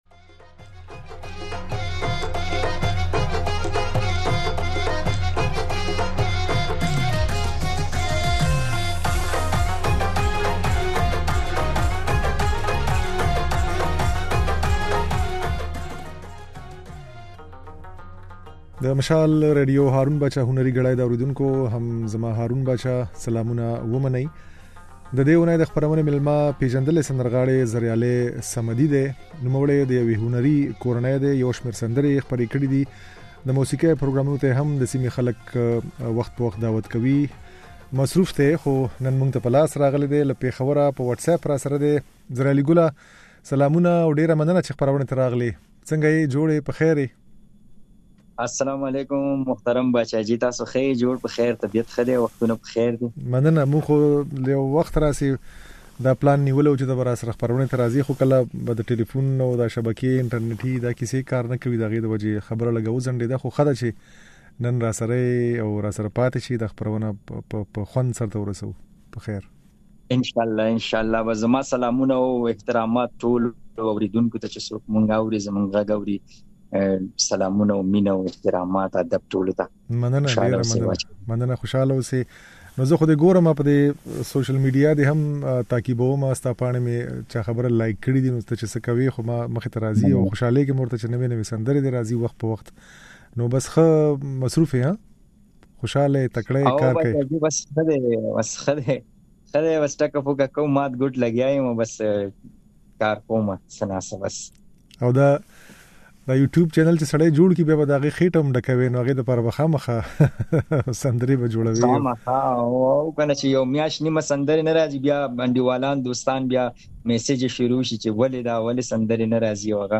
د دې اونۍ د "هارون باچا هنري ګړۍ" خپرونې مېلمه پېژندلی سندرغاړی زريالی صمدی دی.
صمدي وايي، د کډوالۍ د لومړيو وختونو په پرتله يې دا مهال په پېښور کې ژوند ارامه دی. په خپرونه کې د زريالي صمدي دا خبرې او ځينې نوې سندرې يې اورېدای شئ.